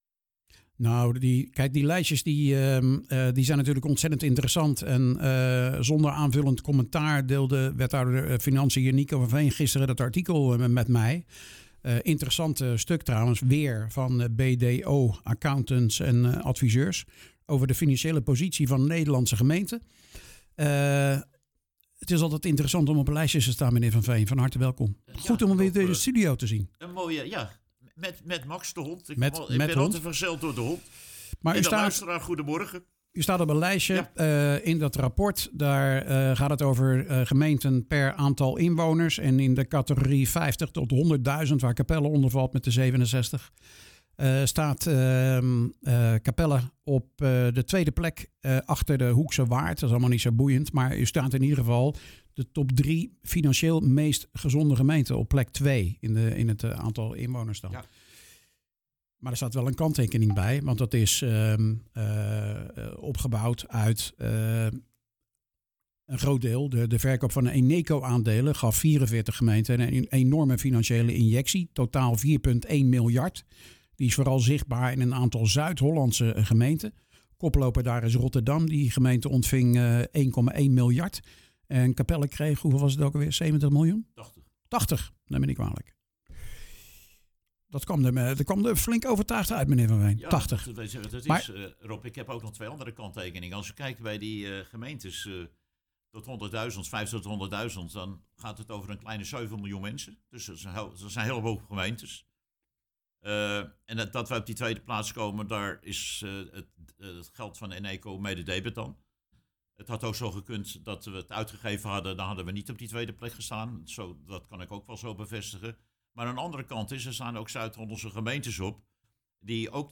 praat erover met wethouder financi�n Nico van Veen